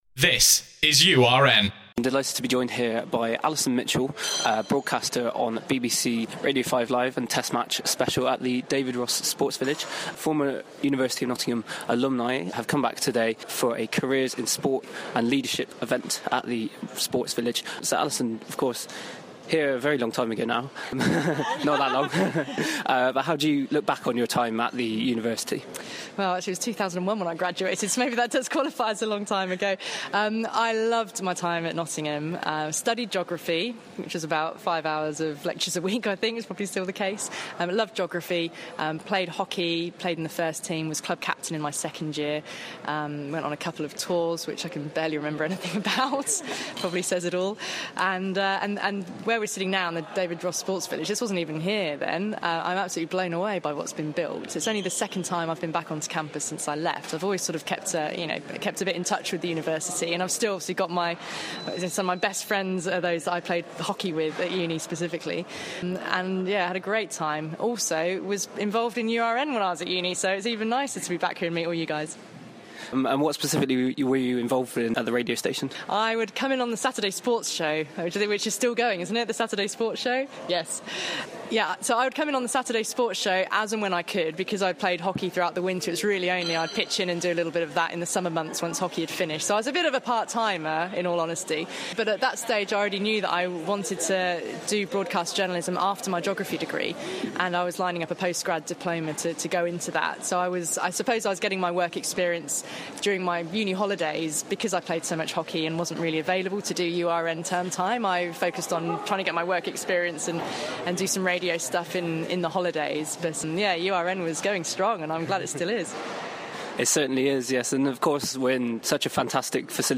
The URN Sport team had the privilege to interview Alison Mitchell from the BBC's Test Match Special on Monday 15th May at a careers in sport conference in the David Ross Sports Village. The former University of Nottingham student reminisced about her time in the East Midlands, spoke about the DRSV and the direction for UoN Sport and detailed what she is looking forward to about this summer's cricket.